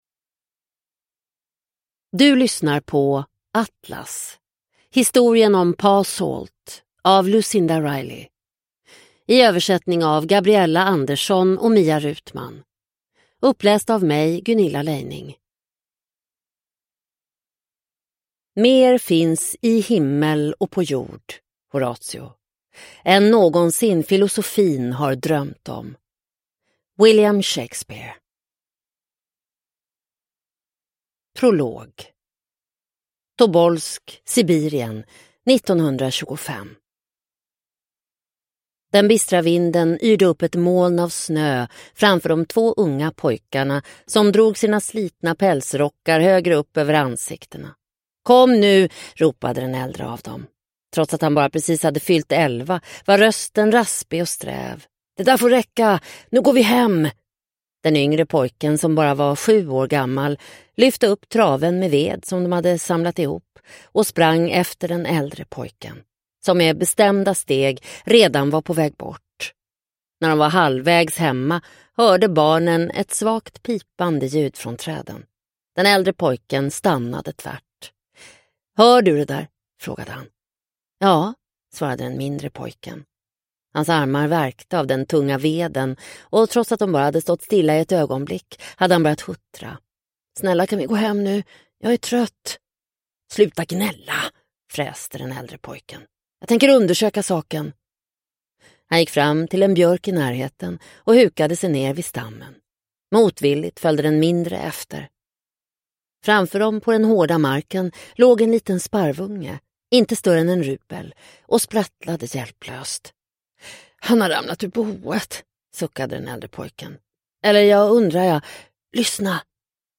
Atlas : historien om Pa Salt – Ljudbok – Laddas ner